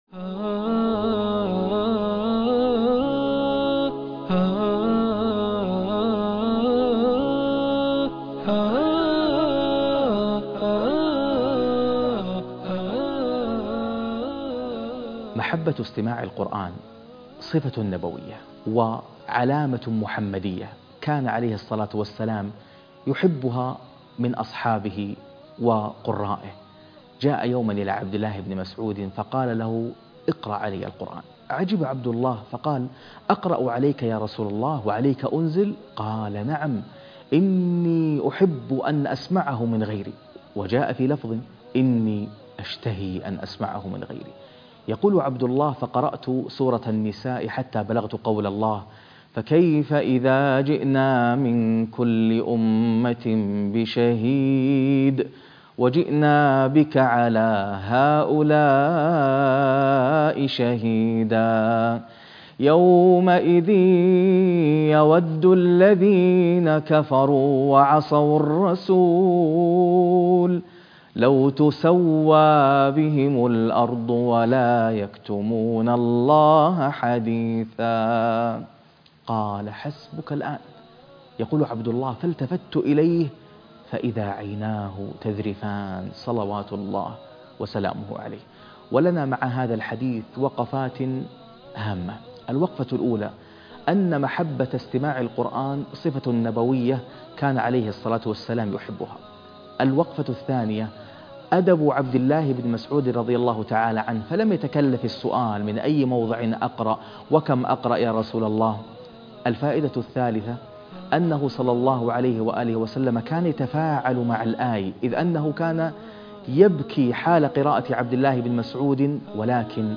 الحلقة السادسة عشر - فاستمعوا إليه - قرآنا عجبا - القاريء ناصر القطامي